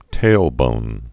(tālbōn)